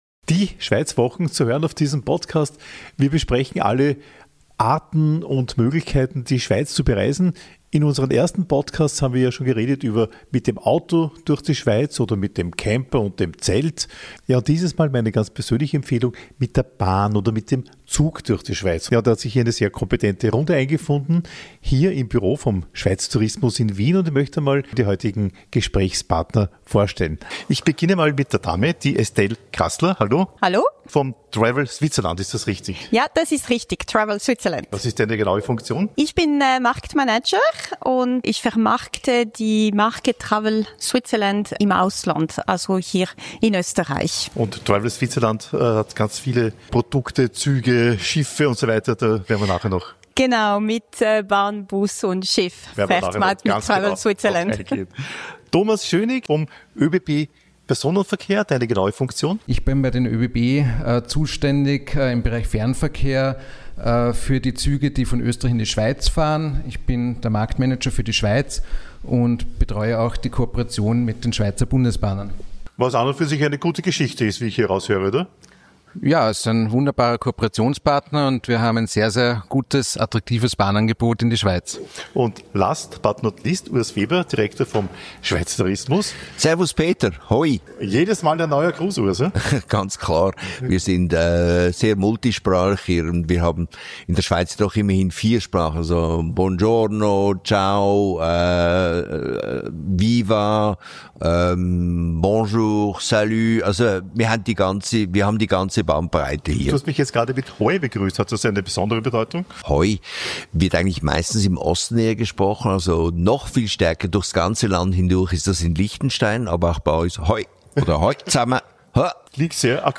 Panoramazüge spielen dabei genauso eine Rolle wie Bergbahnen oder ganz speziell, die Kombination Bahn-Schiff-Seilbahn mit einem Ticket. In dieser Episode erfährst du über die Möglichkeiten, die so ein trip mit dem Zug bietet. Ein Talk